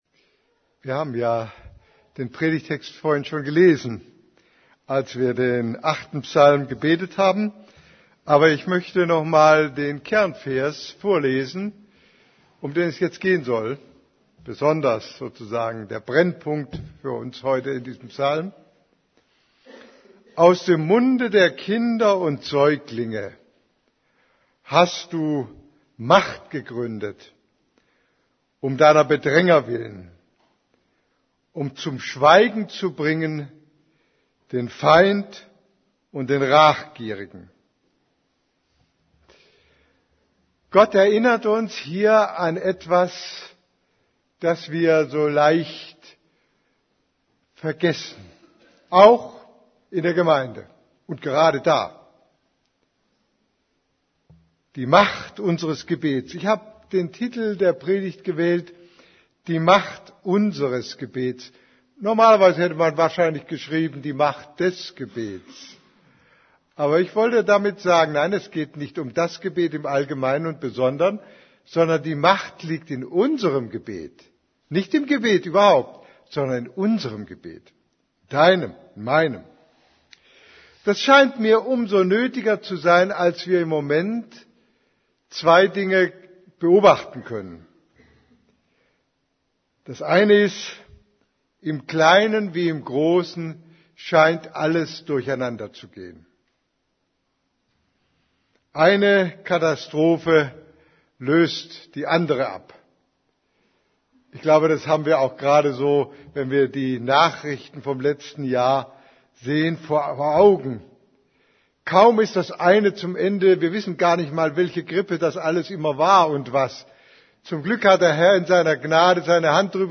> Übersicht Predigten Die Macht des Gebets Predigt vom 22.